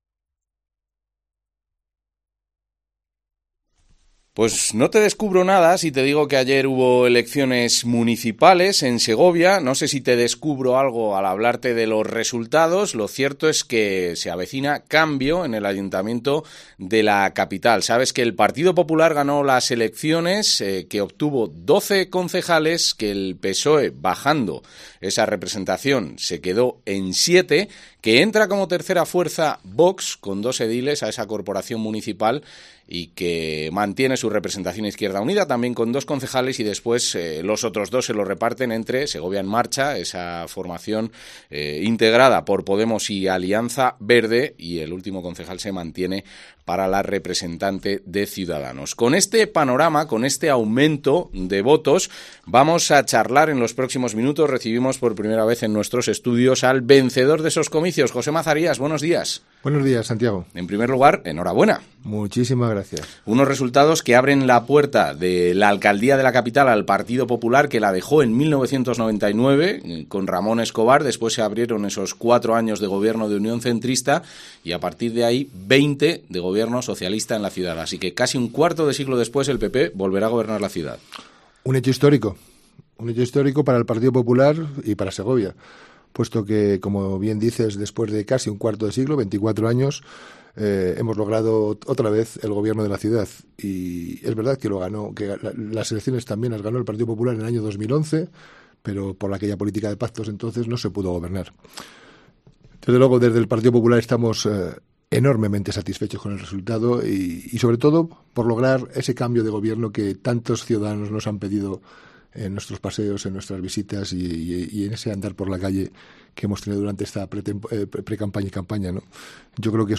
El candidato ganador, José Mazarías, ha concedido a COPE Segovia la primera entrevista tras la victoria. En su paso por estos estudios, ha manifestado su preferencia por llegar a acuerdos puntuales para sacar adelante temas en concreto, antes que cerrar un pacto de gobernabilidad con algunas de las otras formaciones.